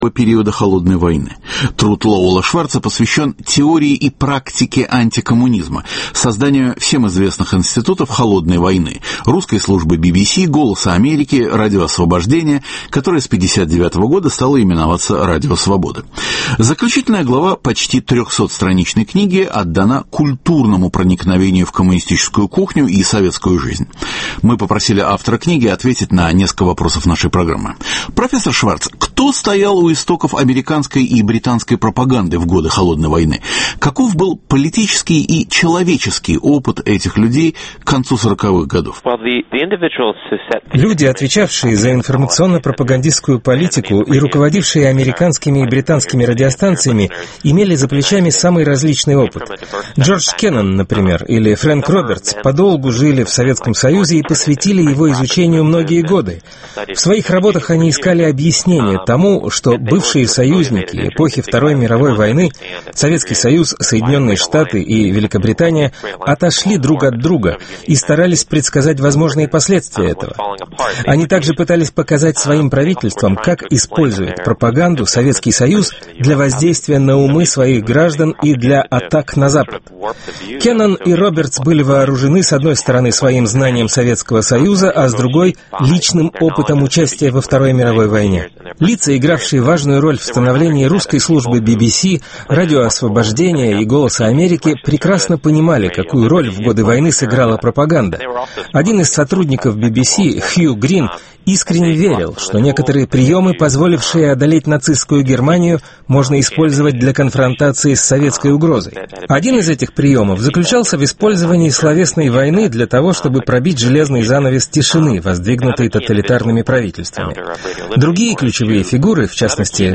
интервью с автором.